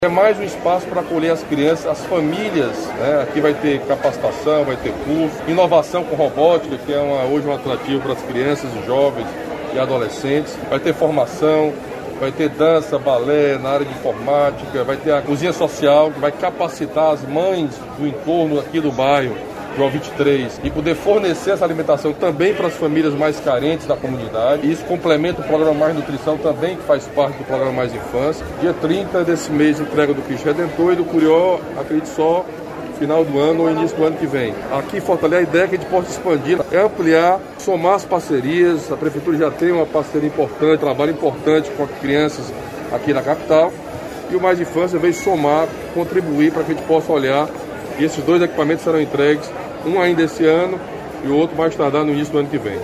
A iniciativa vai ser ampliada em Fortaleza e também no interior. O governador Camilo Santana destacou a importância do novo espaço para integrar a política estadual de valorização da primeira infância.